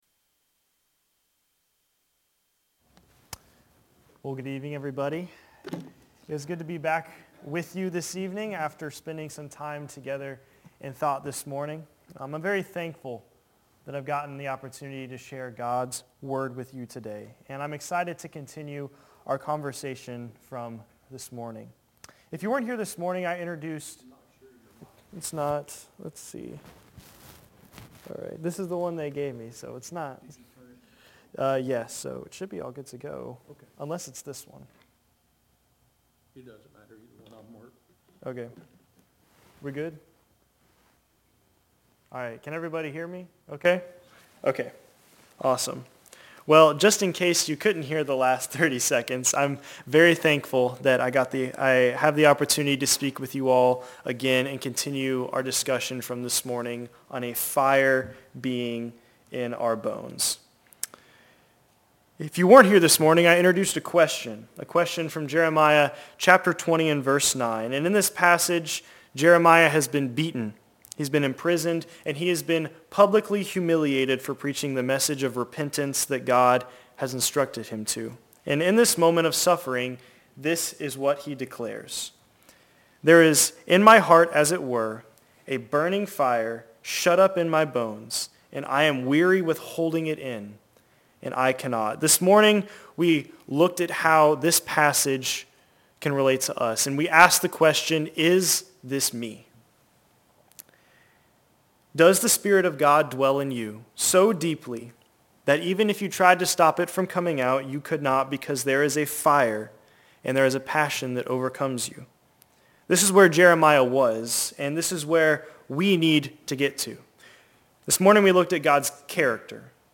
Lakeland Hills Blvd Church of Christ